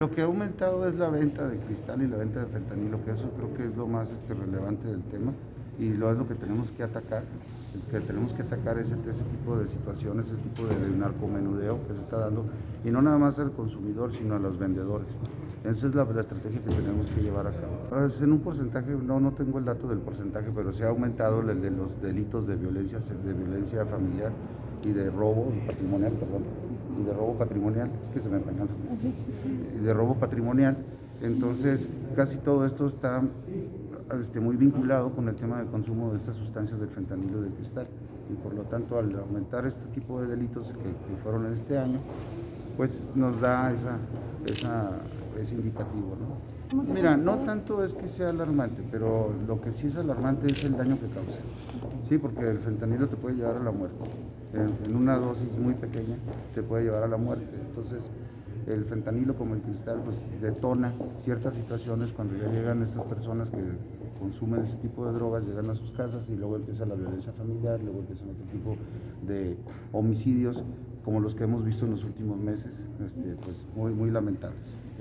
Chihuahua.- La venta y consumo de drogas, especialmente de cristal y fentanilo aumentaron considerablemente en Chihuahua y con ello los delitos de violencia familiar y robo patrimonial, según alertó el fiscal general Roberto Fierro Duarte.